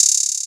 [AFSK] SaciiWorld Hat.wav